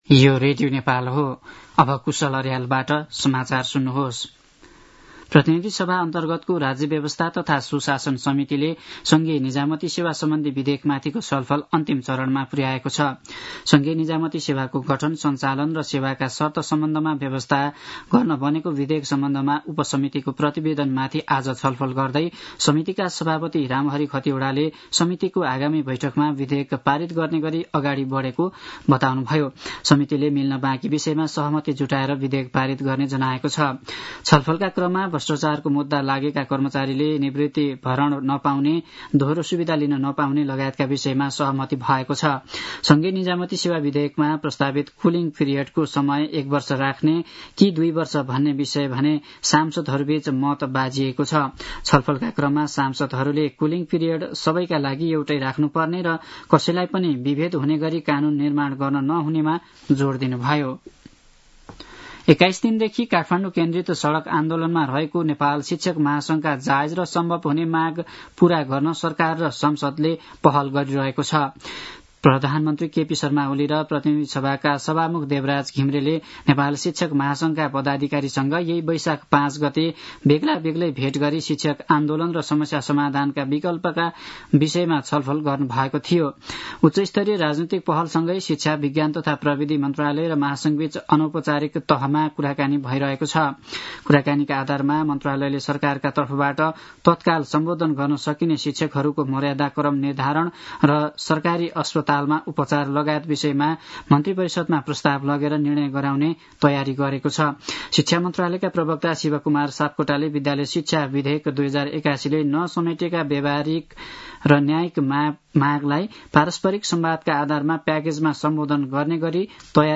दिउँसो ४ बजेको नेपाली समाचार : ८ वैशाख , २०८२
4-pm-news-1-6.mp3